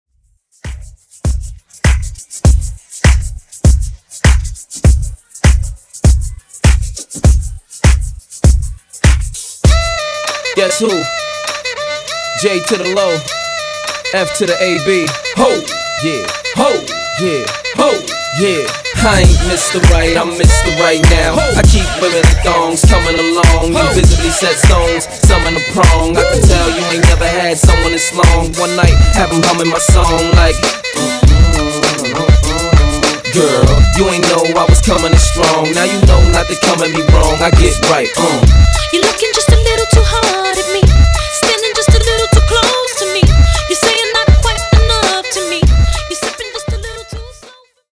Genres: RE-DRUM , TOP40
Clean BPM: 110 Time